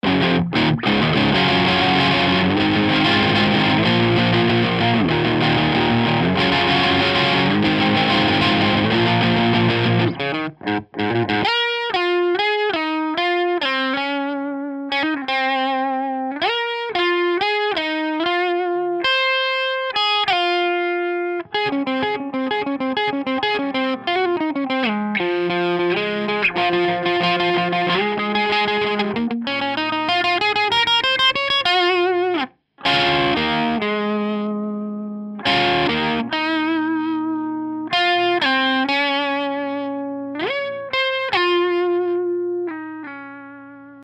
j'ai fait un test de reamp pour un gars sur un autre forum (je connais pas sa gratte) :
Impossible d'avoir cette aigüe avec un V30.